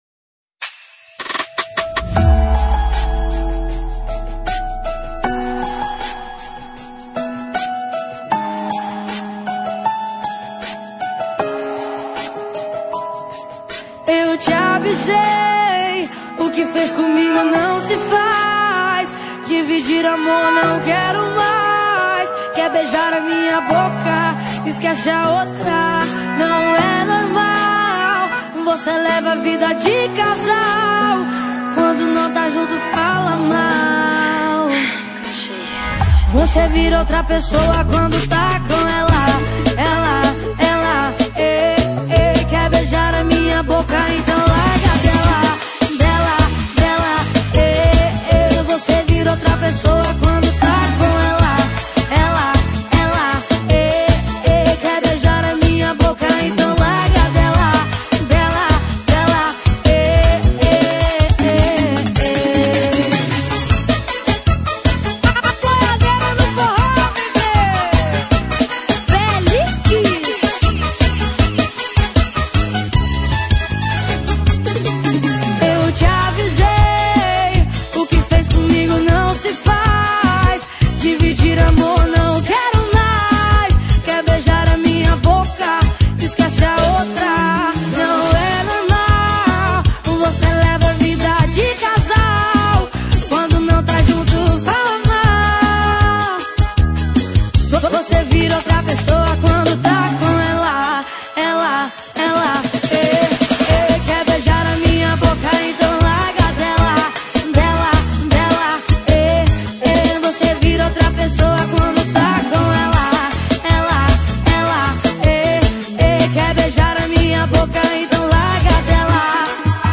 Sertanejas Para Ouvir: Clik na Musica.